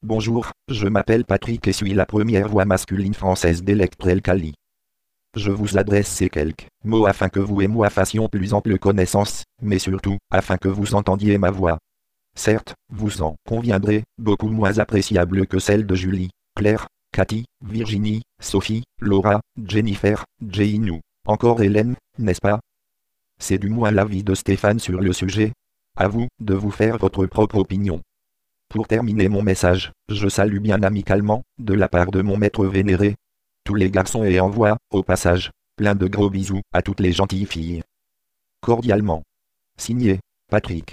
Texte de démonstration lu par Patrick, première voix masculine française d'Electrel Kali (Version 3.17)
Écouter la démonstration de Patrick, première voix masculine française d'Electrel Kali (Version 3.17)